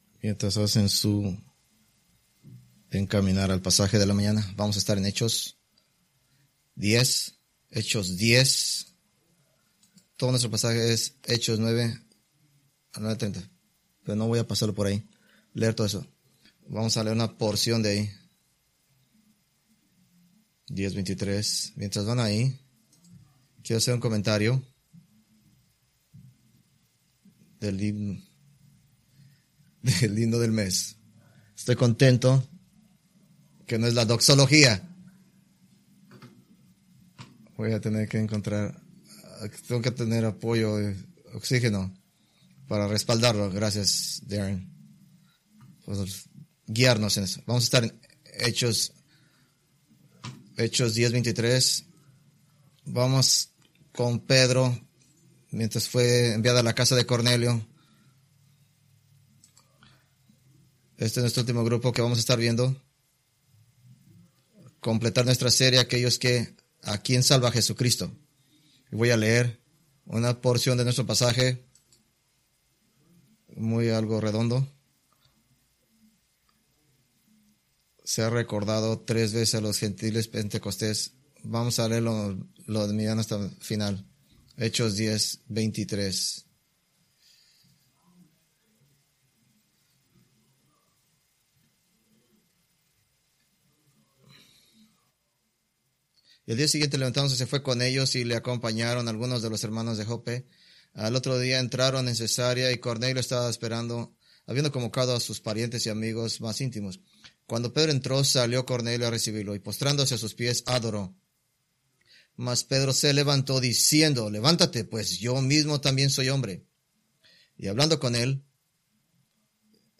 Preached July 7, 2024 from Acts 9:32-11:18